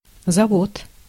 Ääntäminen
IPA: /zɐˈvot/